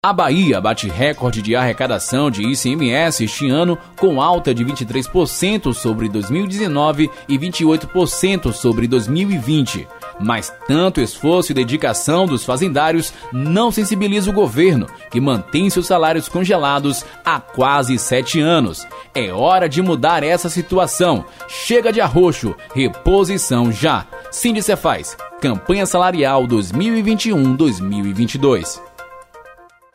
Spot de rádio